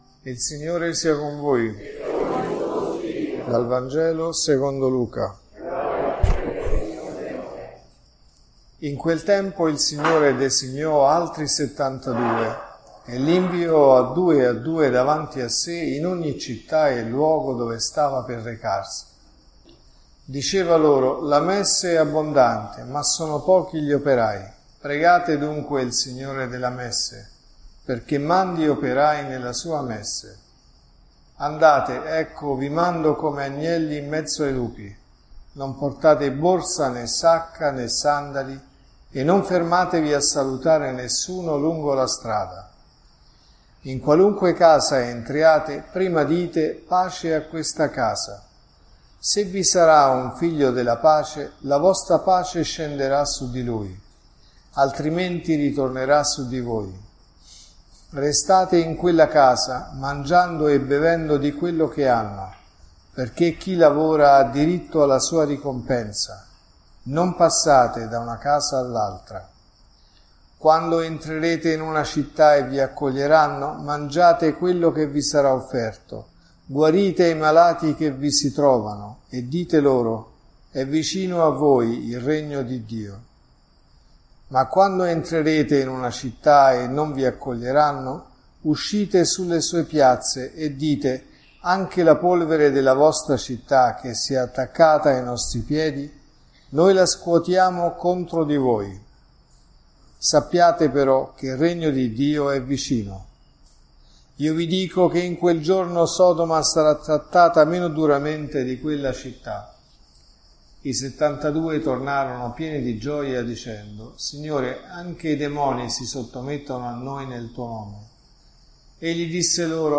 La vostra pace scenderà su di lui.(Messa del Mattino e Sera)